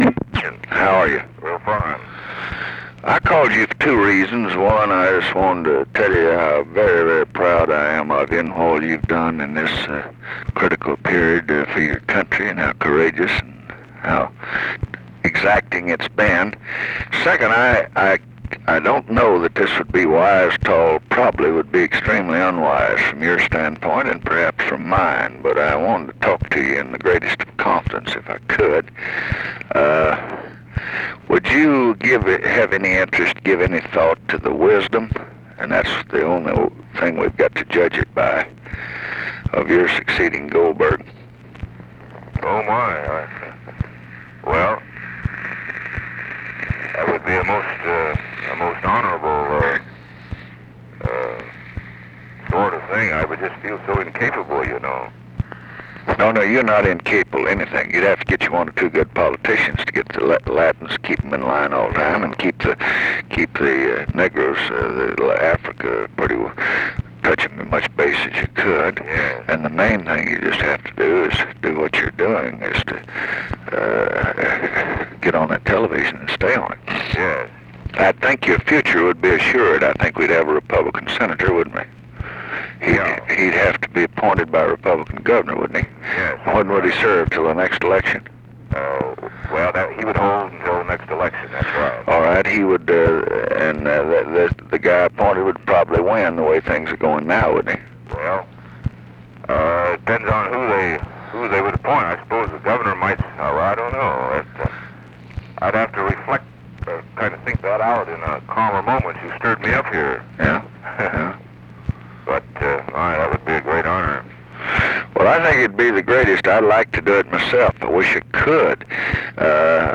Conversation with GALE MCGEE, December 9, 1967
Secret White House Tapes